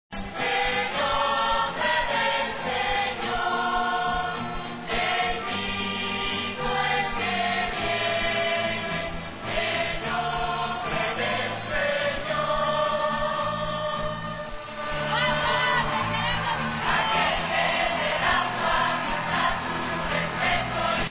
Uroczystość powitalna mająca charakter żywiołowego święta, odbyła się w olbrzymich rozmiarów hangarze, do którego wjechał samolot wiozący Ojca Świętego.
MP3 41 kB -Fragment pieśni "In Nombre del Senior"